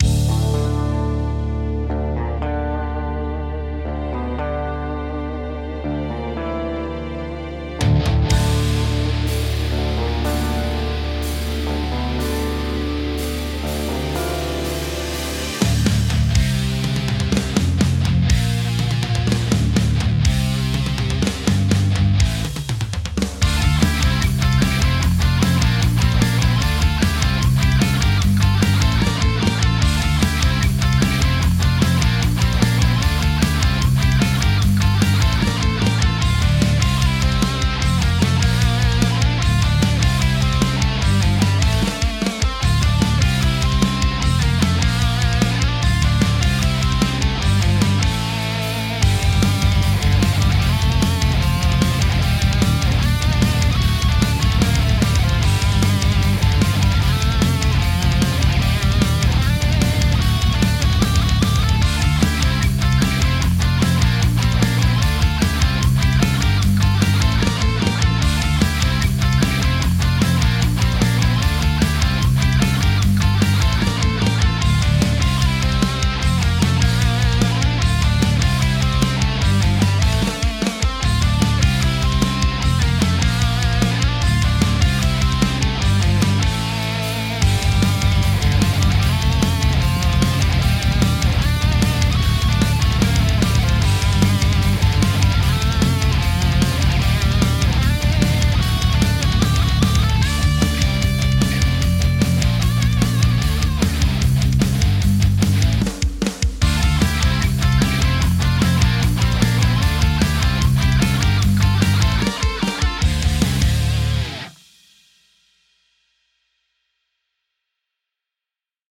A rock/metal track battle song